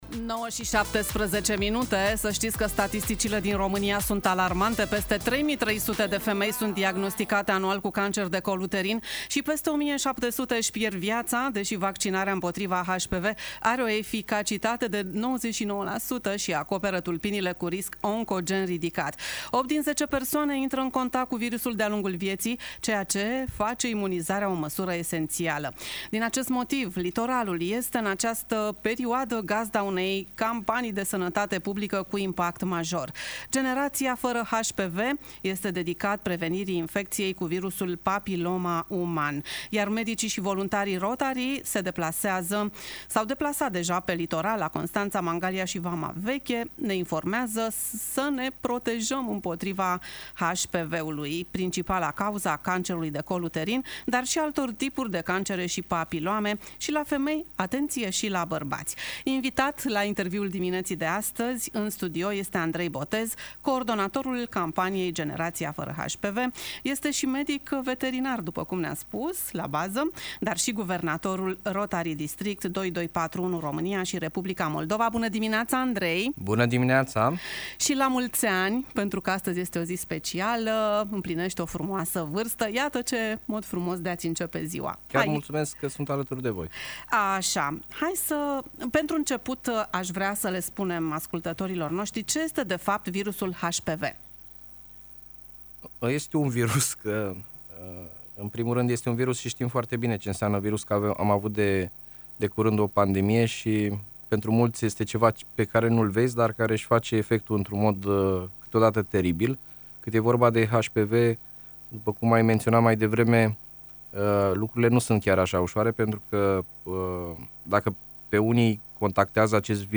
Invitat în studioul Radio Constanța